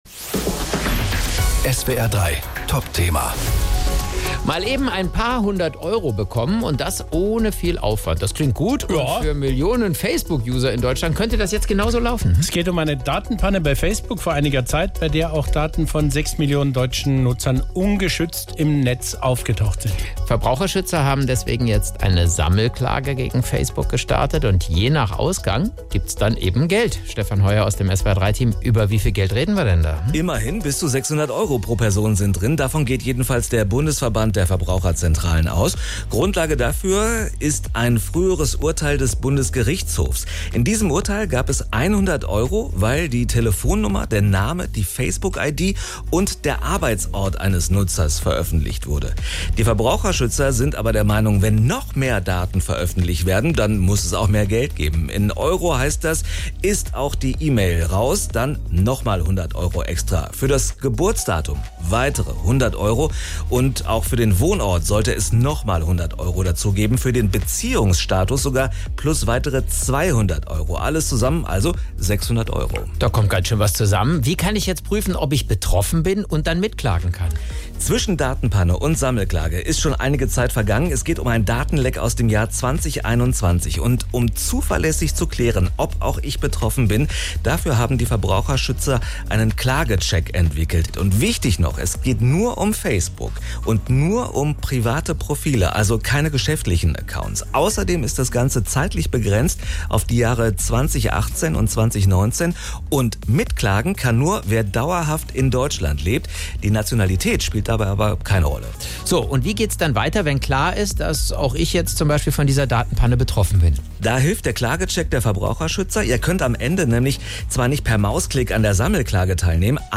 SWR3 Moderator